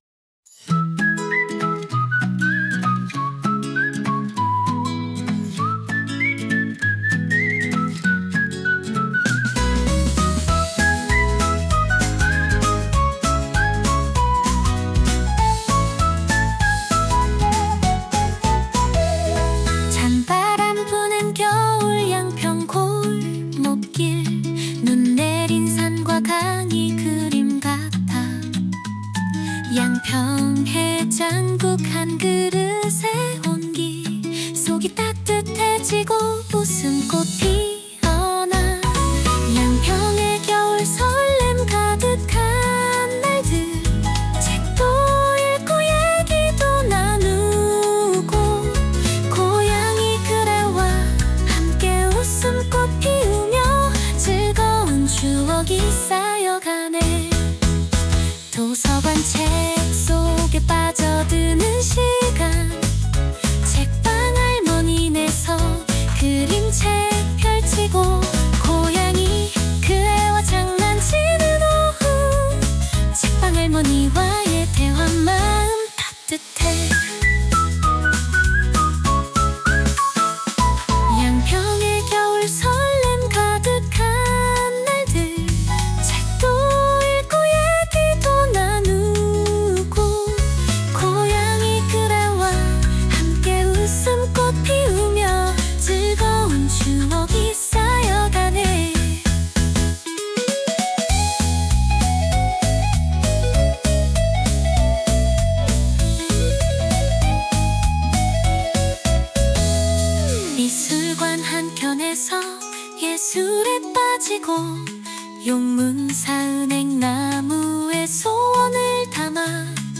chat gpt와 작사도 하고 suno로 작곡을 했다.
cute, duet, funny, hihop song.